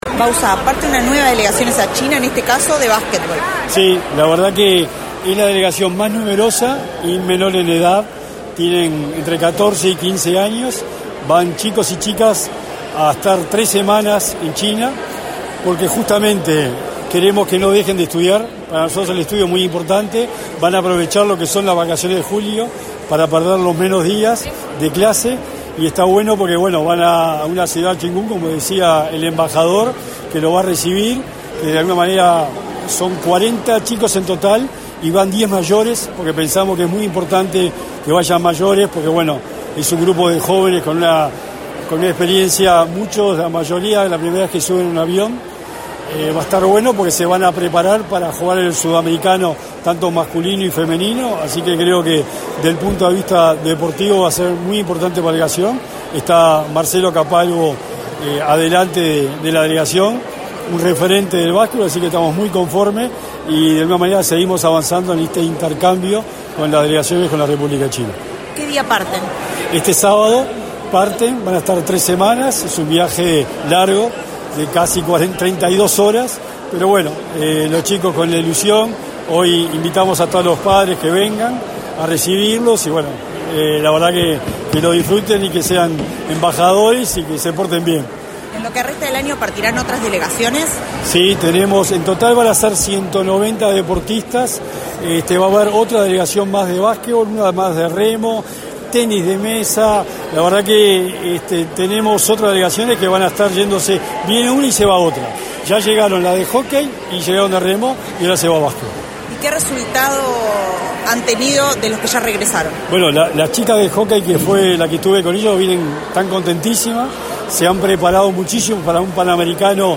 Declaraciones del secretario del Deporte, Sebastián Bauzá
Con la presencia del secretario del Deporte, Sebastián Bauzá, se realizó, este 20 de junio, la despedida de la delegacion de básquetbol que viaja a